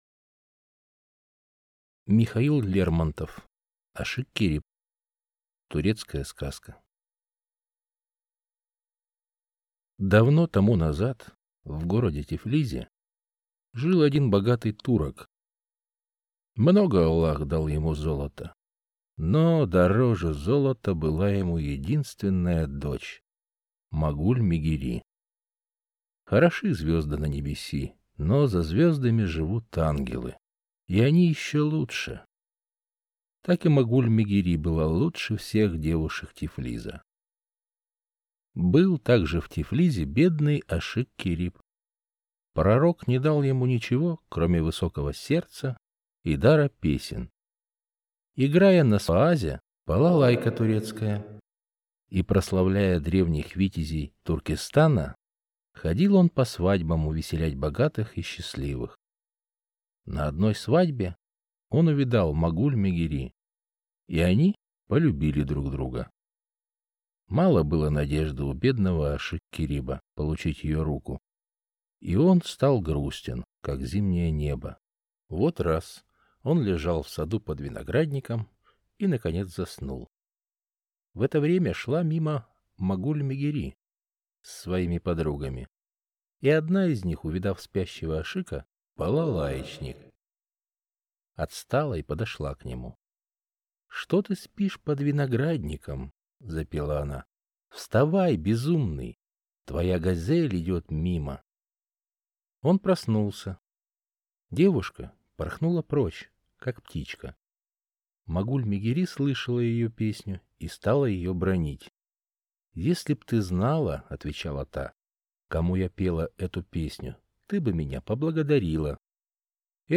Аудиокнига Ашик-Кериб | Библиотека аудиокниг